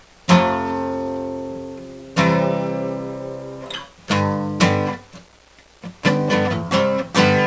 描述：用廉价的木吉他弹了几下。
Tag: 吉他 乱弹